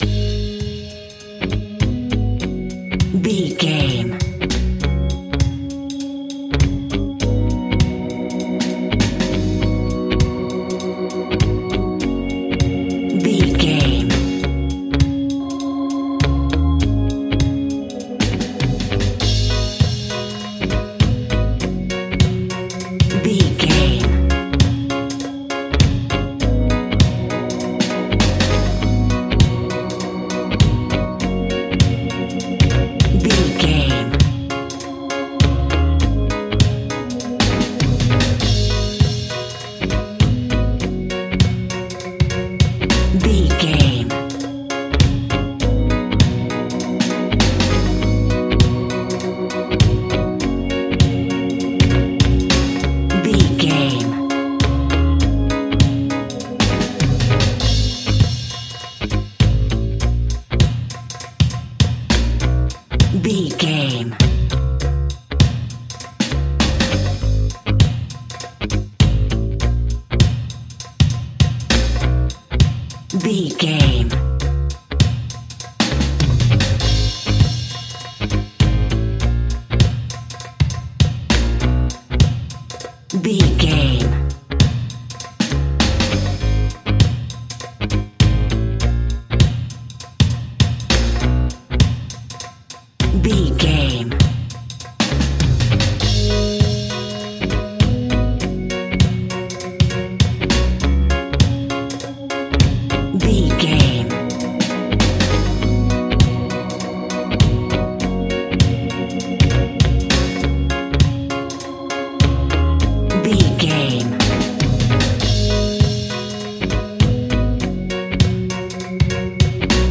A nice bouncy and upbeat piece of Reggae music.
Aeolian/Minor
G#
Slow
laid back
chilled
off beat
drums
skank guitar
hammond organ
percussion
horns